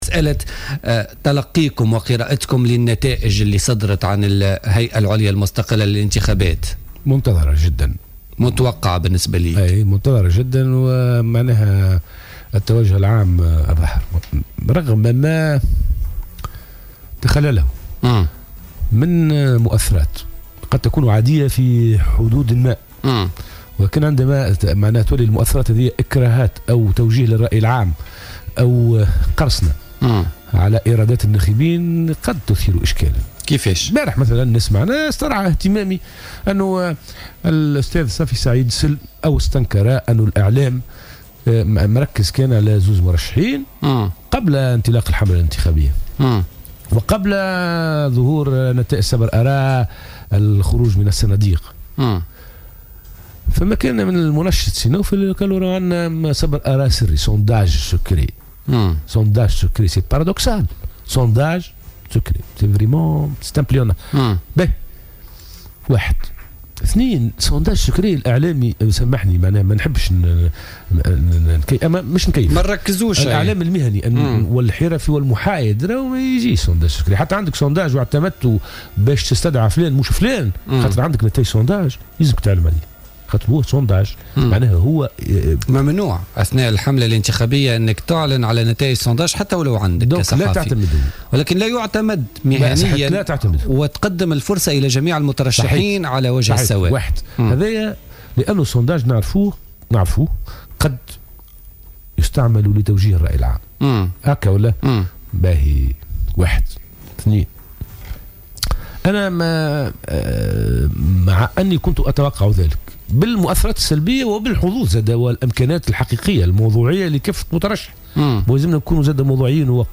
قال عبد القادر اللباوي المرشح المستقل في الانتخابات الرئاسية الفارطة في مداخلة له في برنامج "بوليتيكا" إن بعض المواطنين والناخبين طلبوا منه أموالا مقابل التصويت له في الانتخابات الرئاسية.